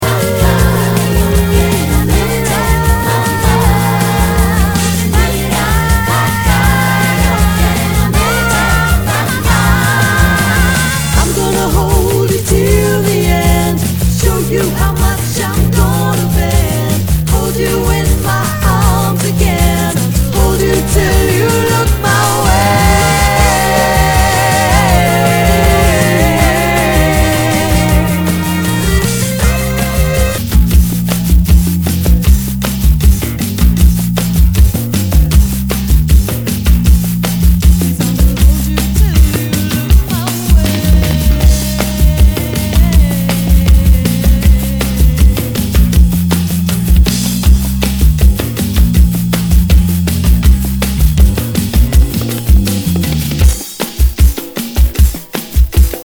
Nu- Jazz/BREAK BEATS
ナイス！アシッド・ジャズ / ダウンテンポ！
盤に歪みあり